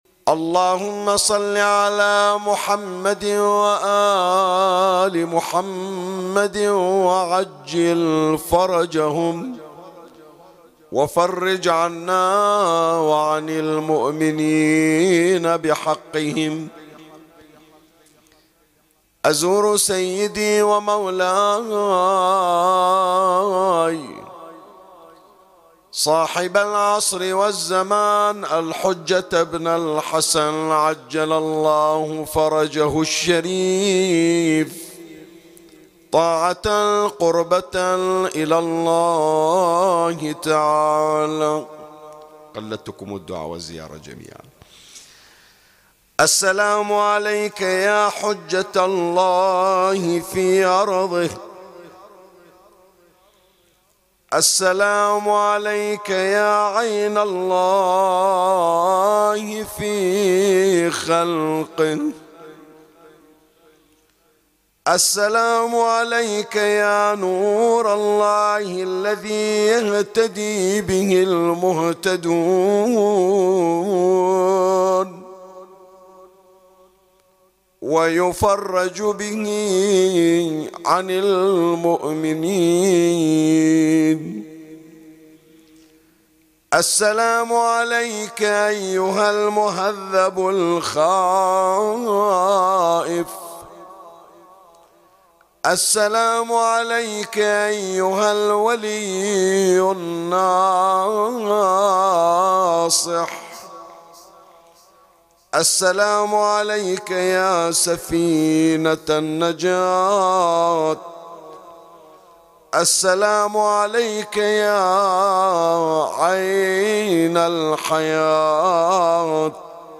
ملف صوتی زيارة يوم الجمعة و هي باسم الإمام المهدي المنتظر (عج)